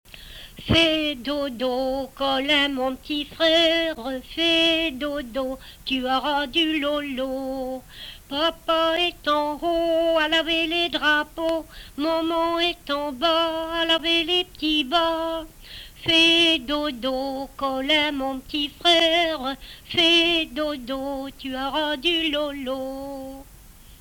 Chanson Item Type Metadata
Emplacement Miquelon